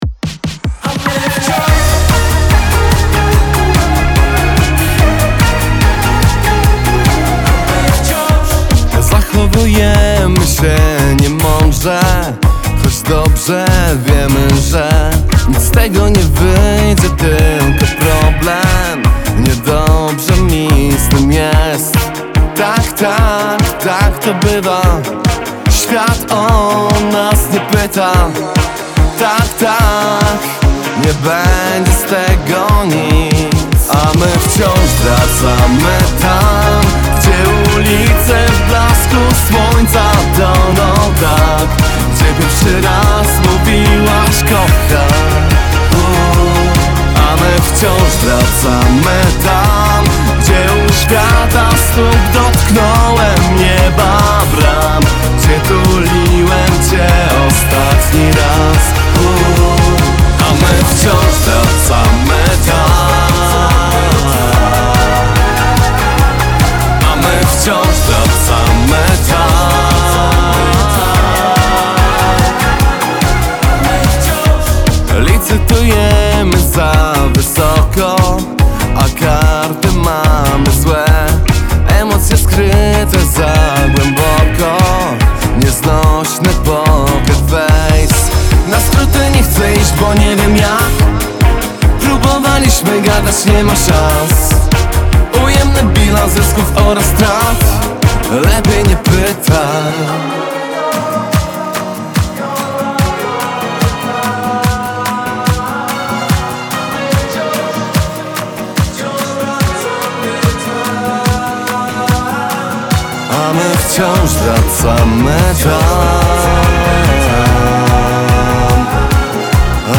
Singiel (Radio)
Współcześnie porywający, a zarazem znajomie sentymentalny.
energiczny i popowy numer